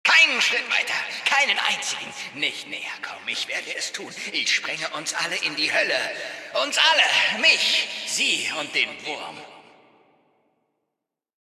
Datei:Maleadult01default freeformdc ffdcadamsmorgan 000cb524.ogg
Charakter: Der Prediger